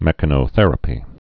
(mĕkə-nō-thĕrə-pē)